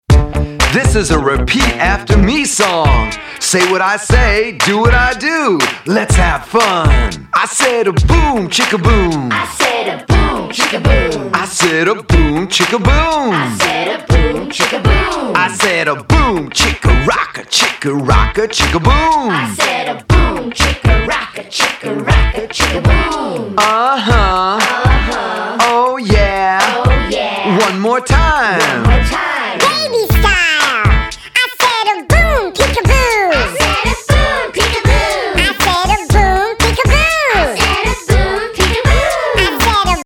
fun, upbeat, dance, exercise and creative movement songs